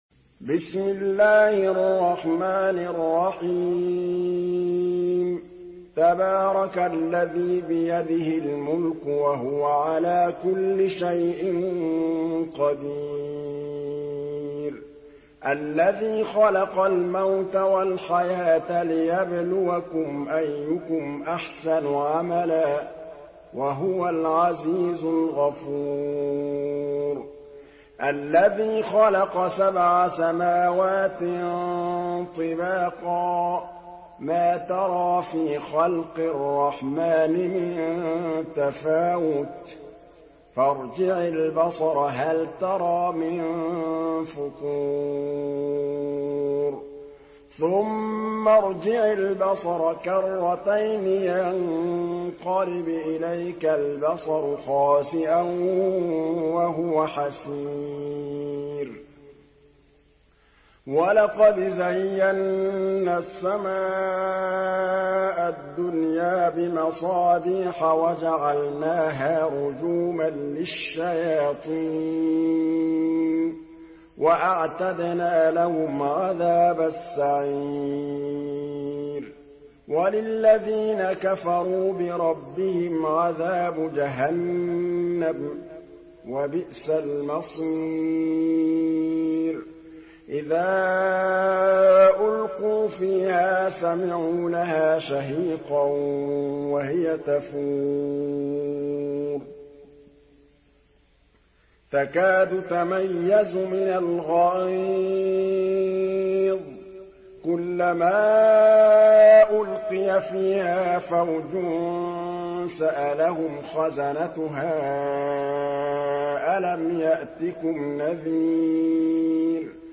Surah Al-Mulk MP3 by Muhammad Mahmood Al Tablawi in Hafs An Asim narration.
Murattal Hafs An Asim